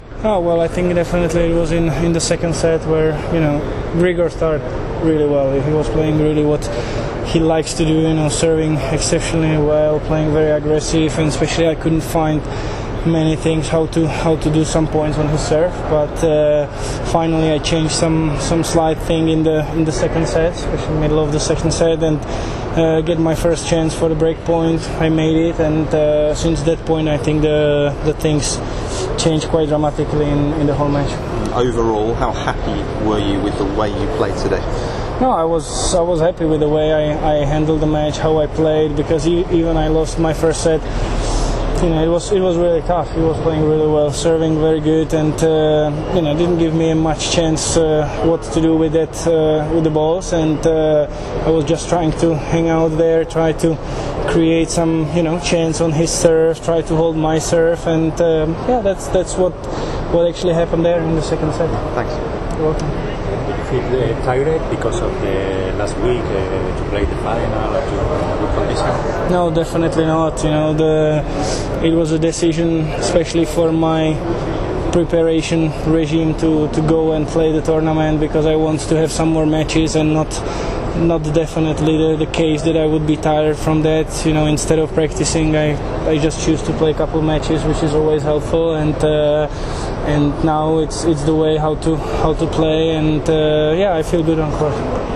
Berdych commenta dopo la partita